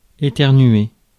Ääntäminen
Synonyymit atchoumer Ääntäminen France: IPA: [e.tɛʁ.nɥe] Haettu sana löytyi näillä lähdekielillä: ranska Käännös Verbit 1. изкихам 2. изкихвам 3. кихам Määritelmät Verbit Expirer violemment , avec un mouvement convulsif des muscles , à la fois par le nez et par la bouche .